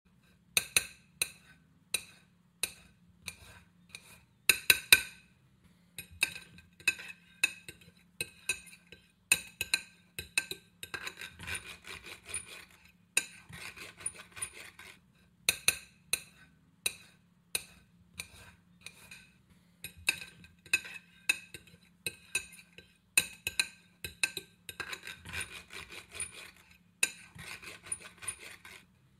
Tiếng Dao Nỉa cắt đồ ăn trên đĩa
Thể loại: Tiếng ăn uống
Description: Tiếng dao nĩa va chạm trên đĩa, âm thanh leng keng, lách cách đặc trưng, kim loại chạm vào sứ, thủy tinh, tạo cảm giác sang trọng, tinh tế, ẩm thực phương Tây. Khi thực khách cắt miếng bít tết, cá hồi hay các món Âu khác, tiếng dao miết nhẹ, tiếng nĩa kéo rê, gõ nhịp nhàng trên bề mặt đĩa... thường được sử dụng trong chỉnh sửa video ẩm thực, cảnh quay nhà hàng, bàn tiệc sang trọng.
tieng-dao-nia-cat-do-an-tren-dia-www_tiengdong_com.mp3